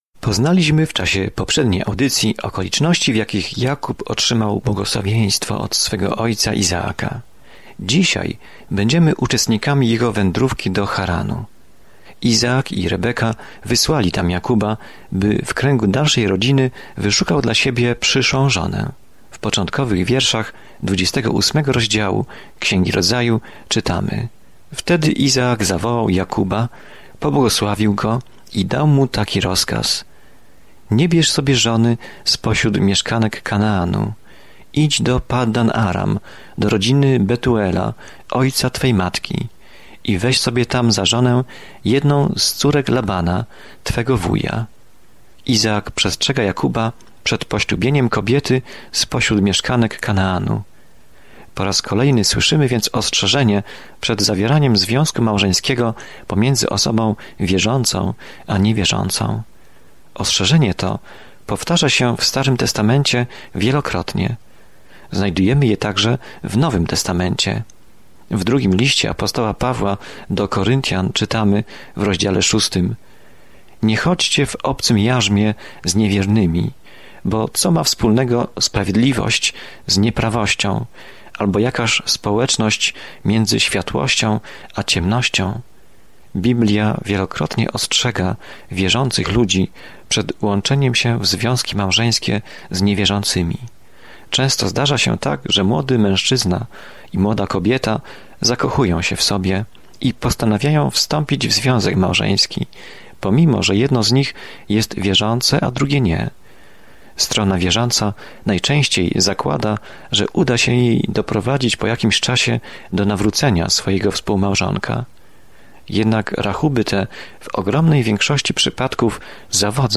Codziennie podróżuj przez Księgę Rodzaju, słuchając studium audio i czytając wybrane wersety słowa Bożego.